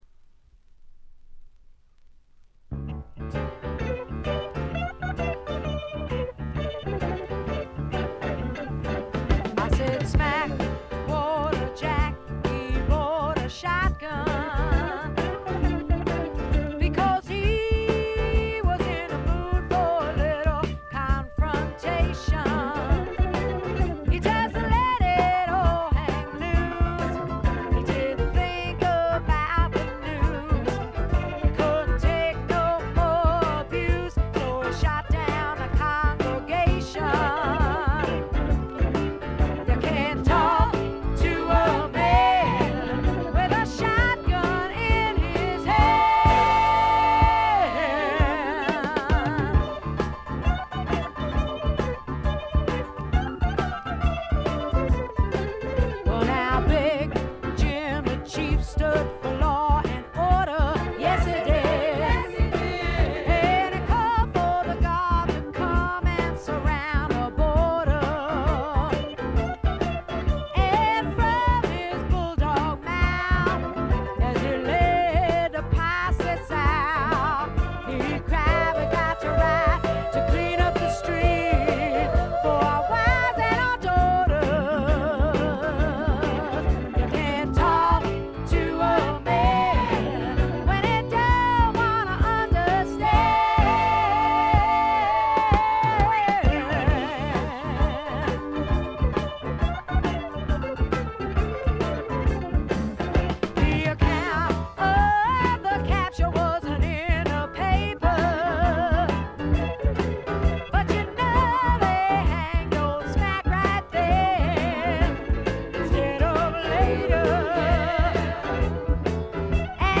これ以外はほとんどノイズ感無し。
基本は軽いスワンプ路線。
試聴曲は現品からの取り込み音源です。